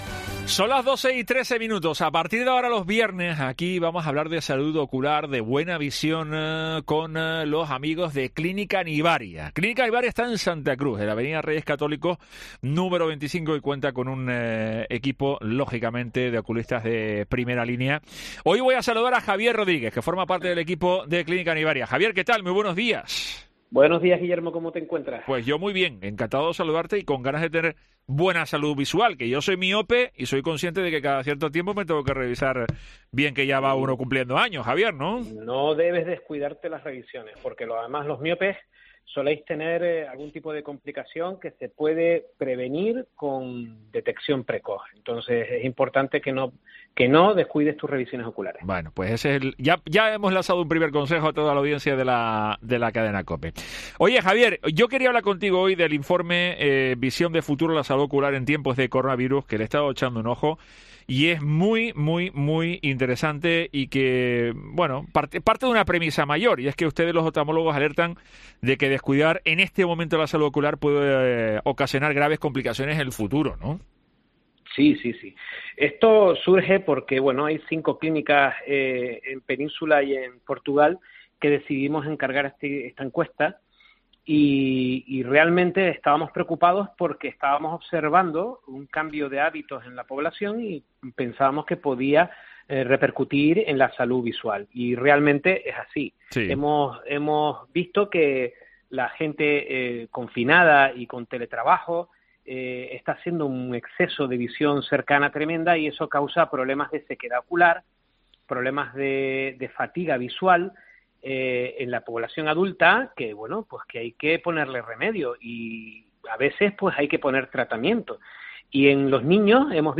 PUBLIRREPORTAJE